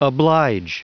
Prononciation du mot oblige en anglais (fichier audio)
Prononciation du mot : oblige